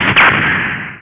bossfirebullets.wav